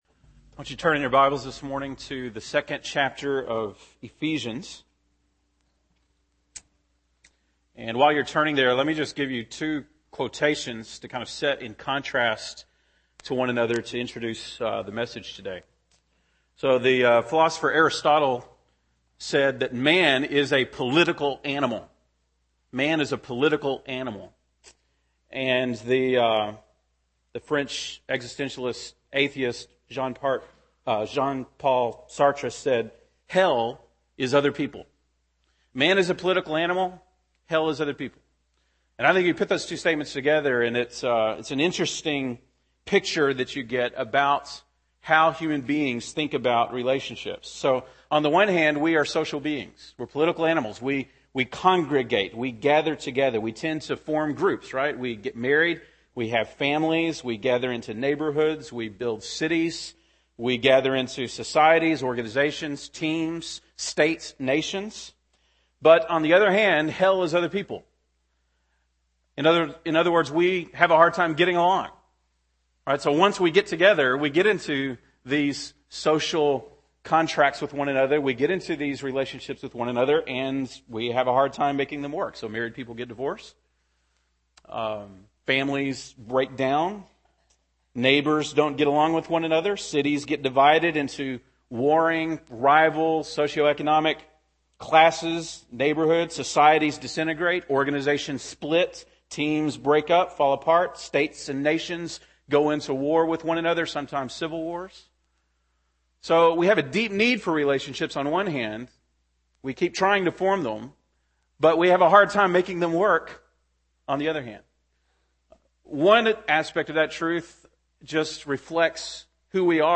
January 23, 2011 (Sunday Morning)